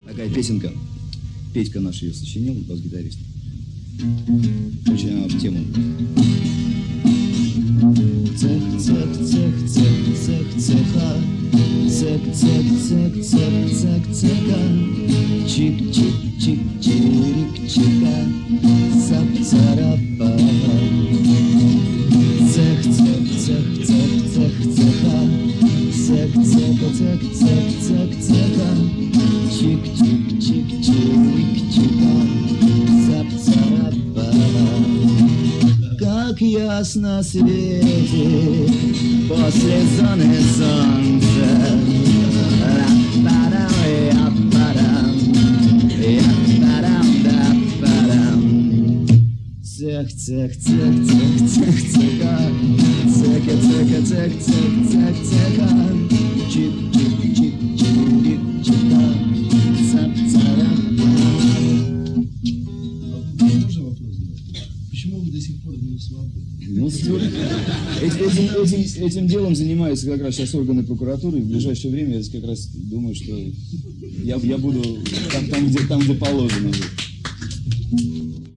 Домашний концерт 88 год. 3Мб